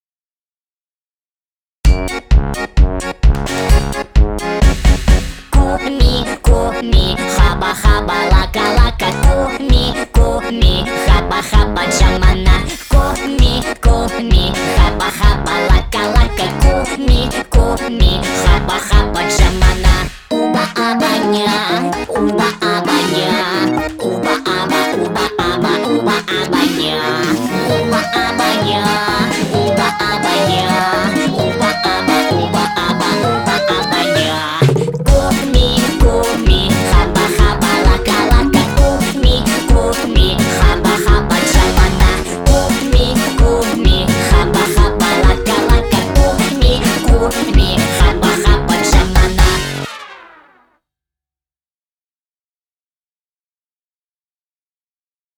• Качество: 320, Stereo
веселые
Очень веселая песенка из мультика